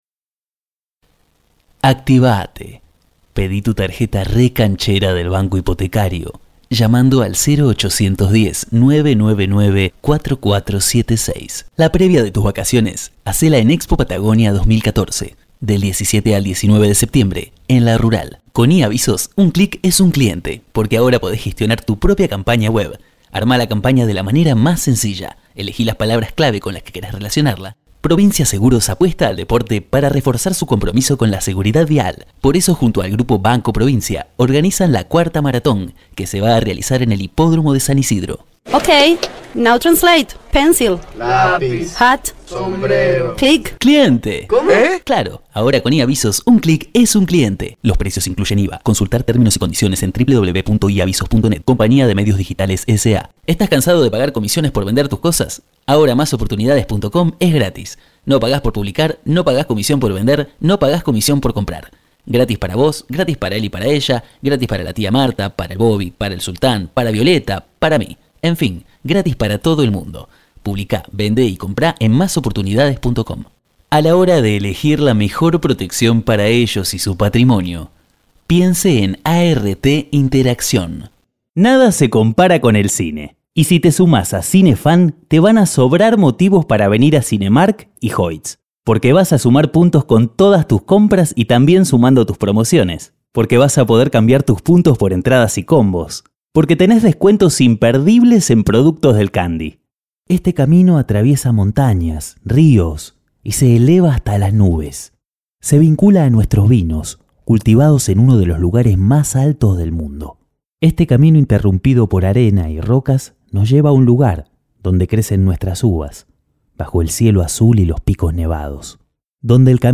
Rápido y profesional con estudio propio de alta calidad. Vos brillante, agradable y versátil.
Sprechprobe: Werbung (Muttersprache):
Bright, powerful and versatile voice.